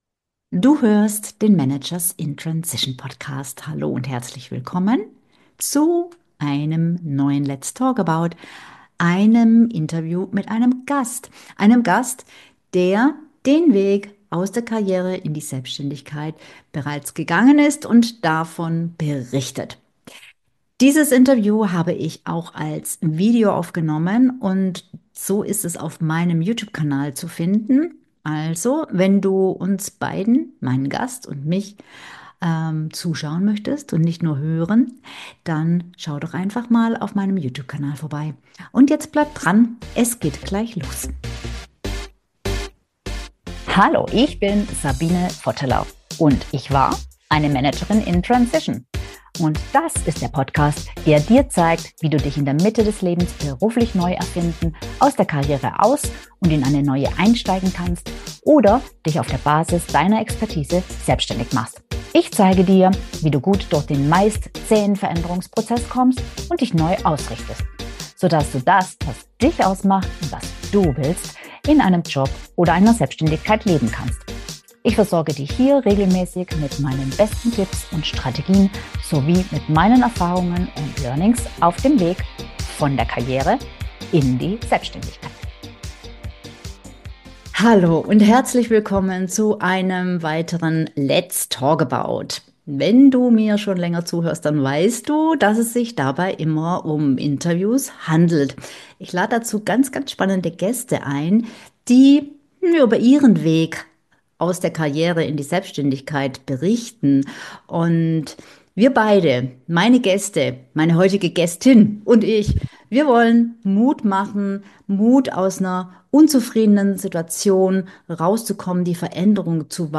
In diesem Interview erfährst du außerdem - wie sie schon früh zu der Überzeugung kam, dass es im Grunde egal war, was sie machte, - welcher belanglose Spruch für sie endgültig festzementierte: Ich werde erfolgreich!